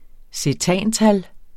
Udtale [ seˈtæˀn- ]